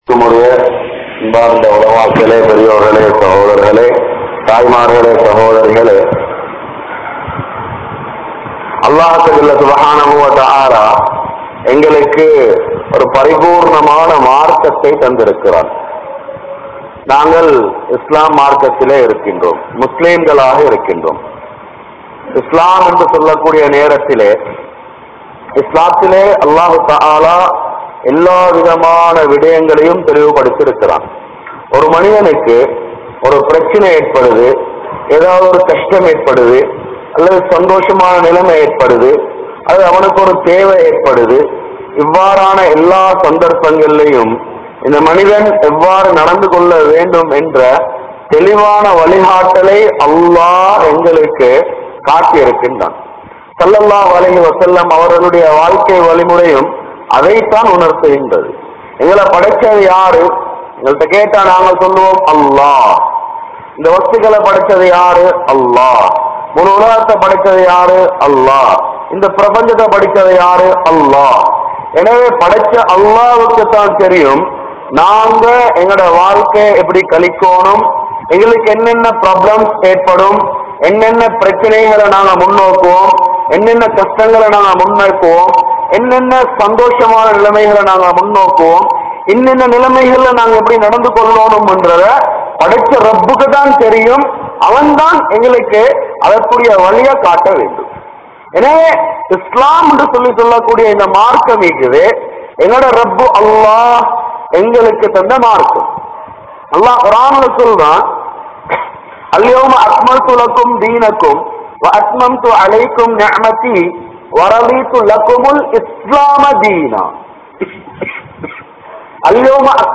Muslimkalidam Irunthu Parikkap Patta Arasiyal & Maruthuvam (முஸ்லிம்களிடம் இருந்து பரிக்கப்பட்ட அரசியல் & மருத்துவம்) | Audio Bayans | All Ceylon Muslim Youth Community | Addalaichenai
M.R Reception Hall